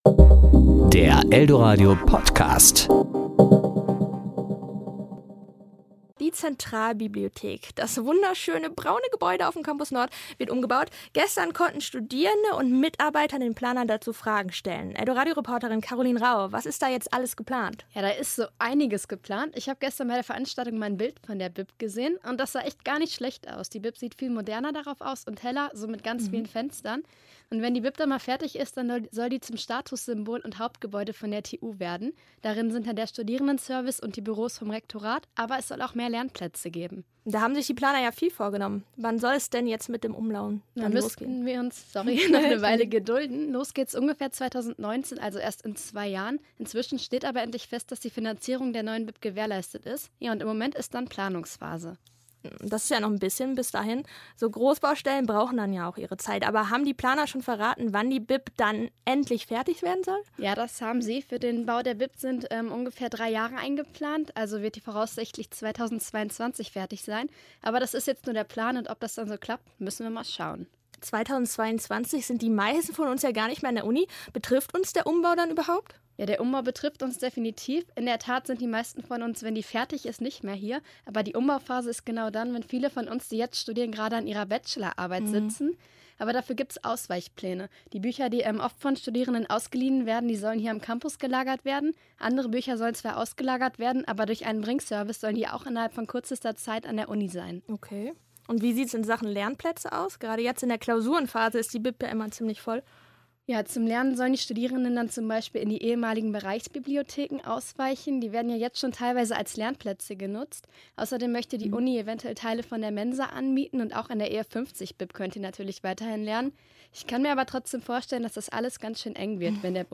Bei einer Veranstaltung im alten Bib-Gebäude konnten Studierende und Mitarbeiter den Planern Fragen zum Umbau stellen.